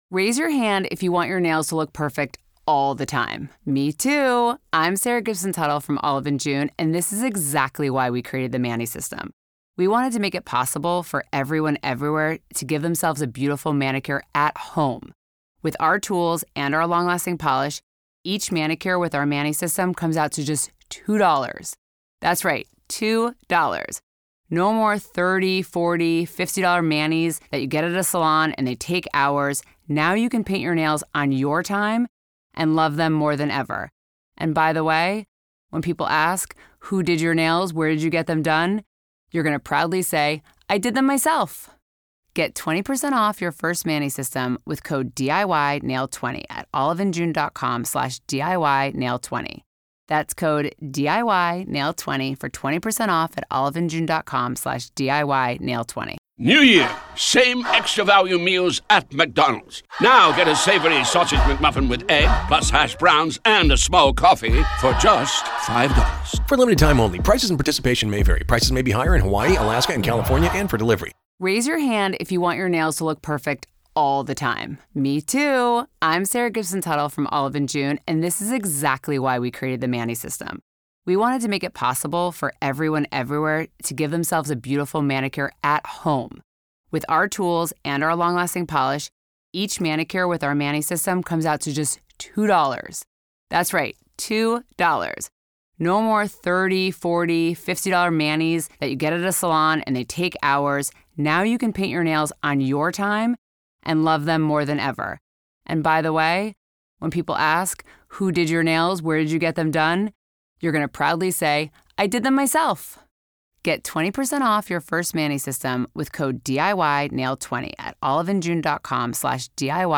Our listeners call in, sharing their perspectives and emotions, as we explore the circumstances that led to such a devastatin...